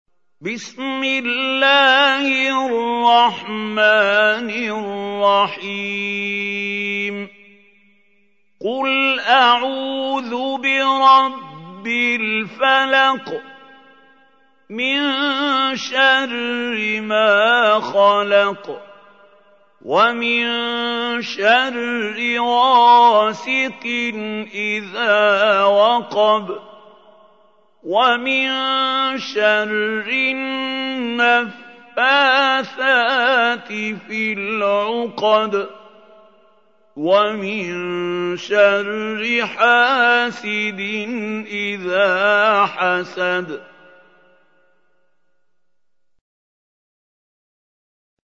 Чтение Корана > ХАЛИЛ ХУСАРИ